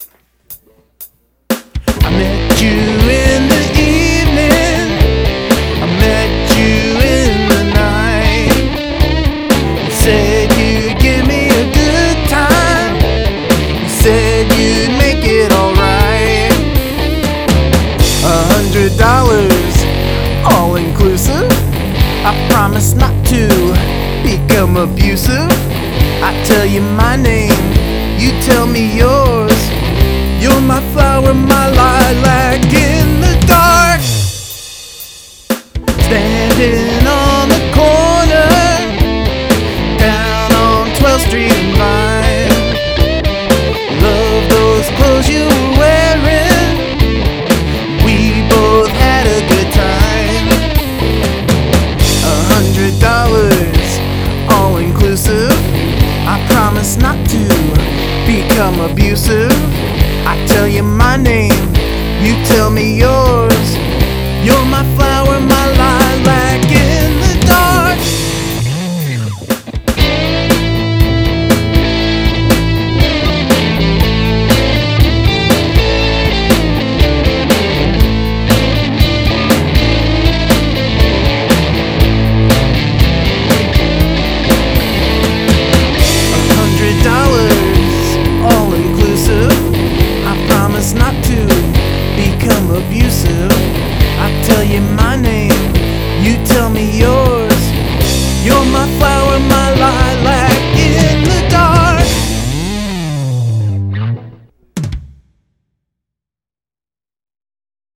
I really like the fuzzy guitar on this one.
Very cool lead! And the song chugs along quite nicely.